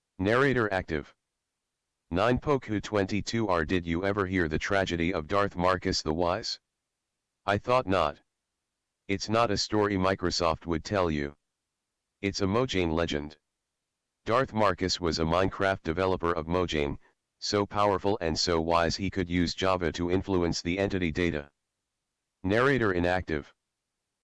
Narrator reads scoreboard team color codes in player names
WithColor.mp3